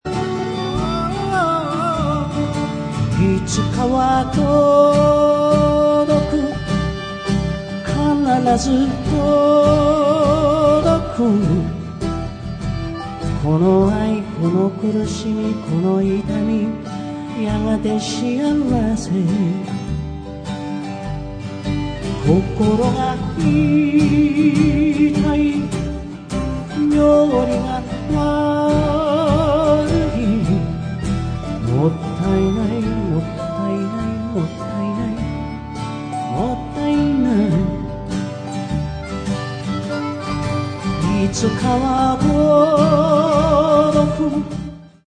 ライブアルバム
2005年9月6・7日　日本武道館にて収録